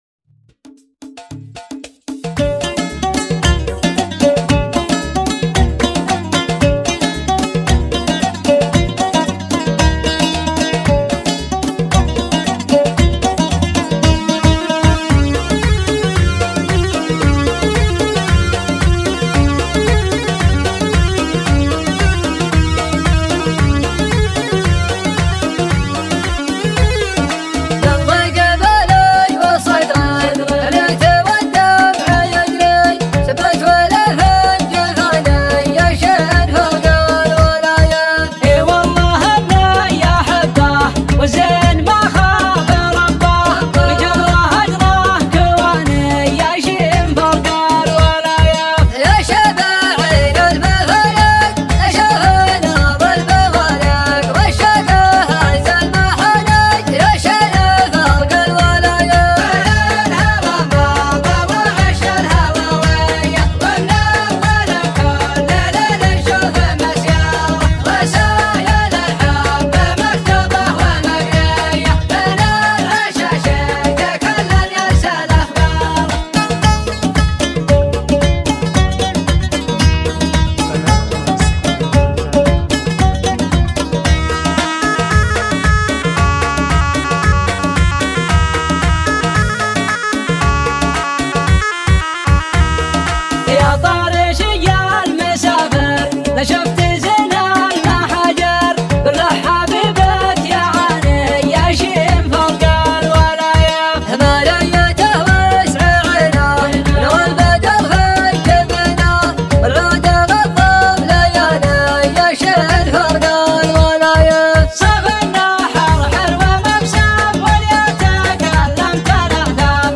شيلات طرب
شيلات مسرعة